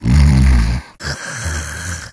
sandy_die_vo_02.ogg